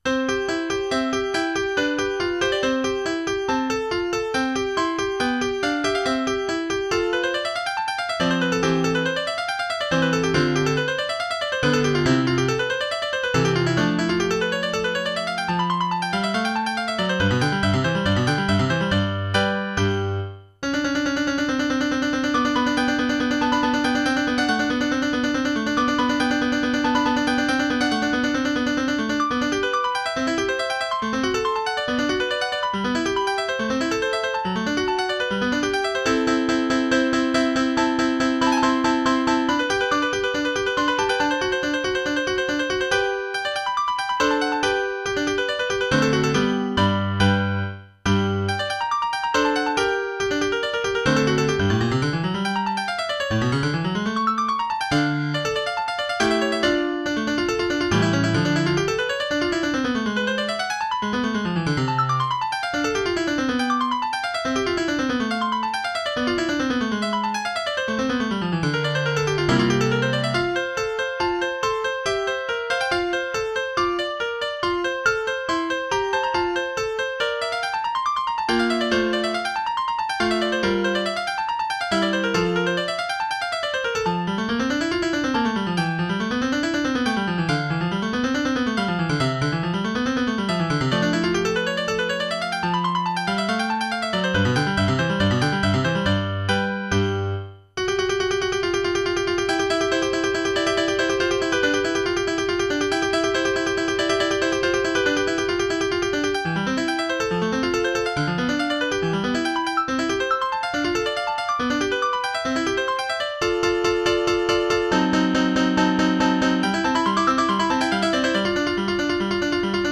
public domain midi